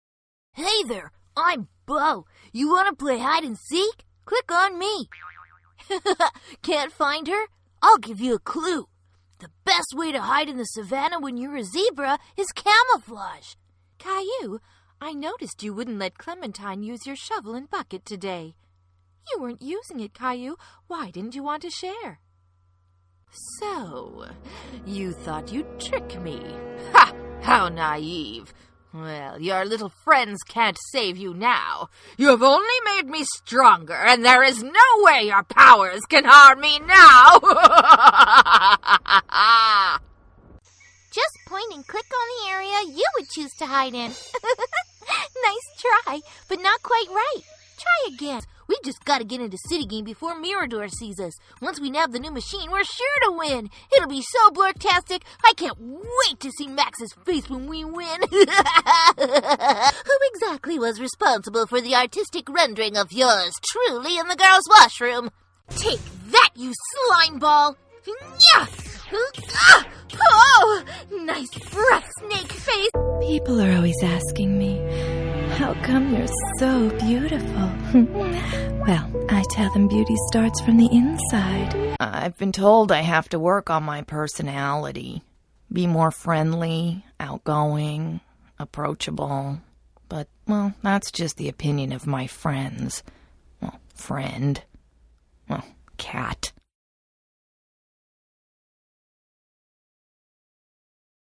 Animation - EN